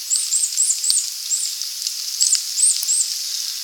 Family of Chickadees feeding in Chestnut Oaks during a heavy rain (315kb).  This sound is very high-pitched and could not be compressed without losing some quality.
chickadee_family_short563.wav